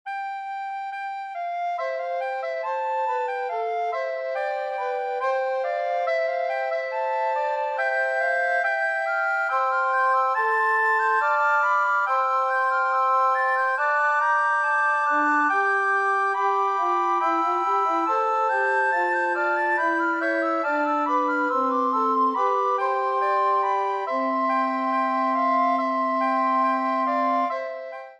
S A T B
Each piece (except, curiously, the first!) begins with the standard canzon motif of long-short-short (the so-called dactyl pattern). The parts sometimes engage in a call-and-response with each other, and sometimes play together.